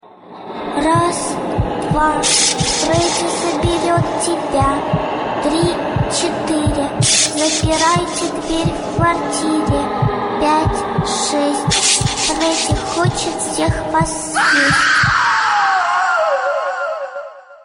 Категория: Музыка из фильмов ужасов